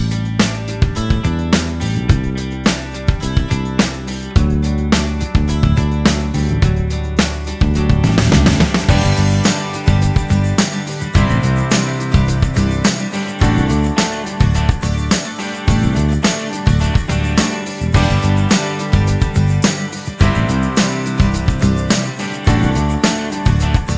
No Backing Vocals Irish 3:53 Buy £1.50